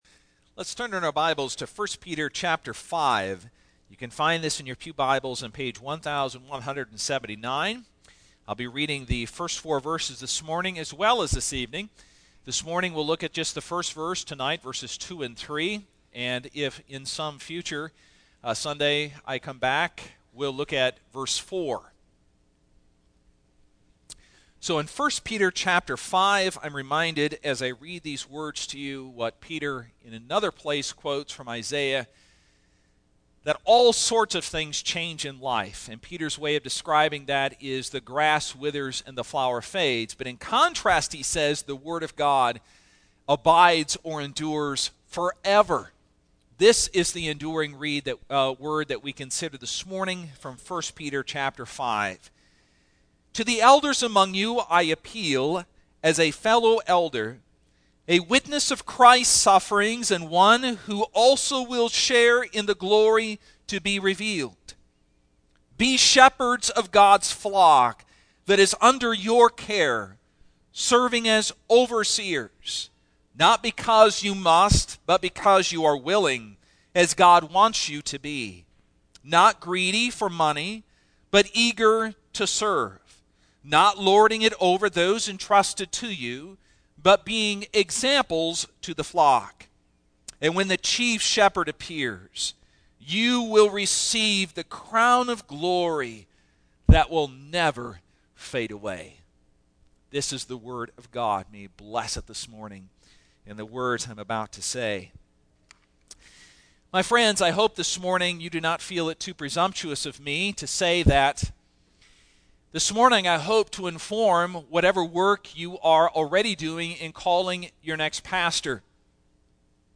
Single Sermons Passage: 1 Peter 5:1-4 %todo_render% « Pastor’s Portrait